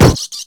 fall.ogg